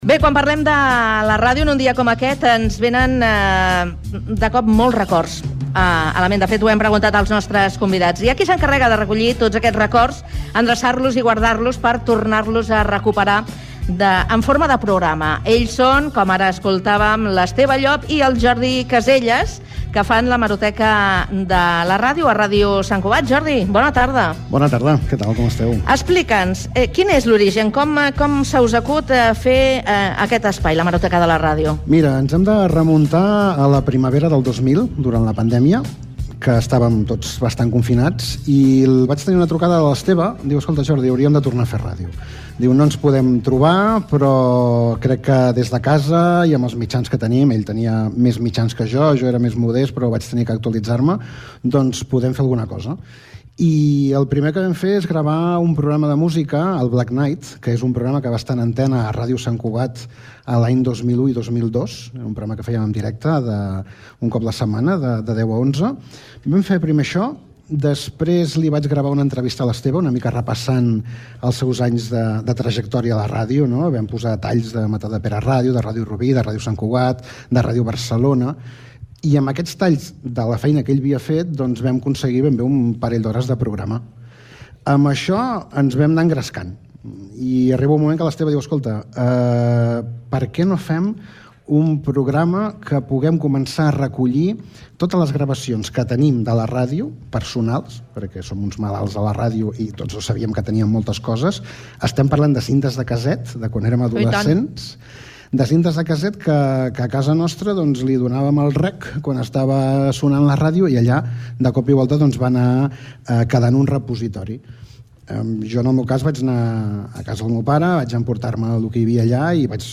Gènere radiofònic Info-entreteniment
"Connectats" és el magazine de tarda de La Xarxa